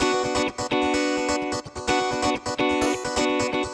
VEH3 Electric Guitar Kit 1 128BPM
VEH3 Electric Guitar Kit 1 - 6 B min.wav